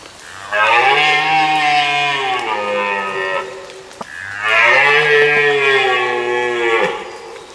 W tym czasie, od zmierzchu do świtu, samce jeleni, czyli byki, chcąc zwabić łanie i odstraszyć rywali, wydają głośne odgłosy.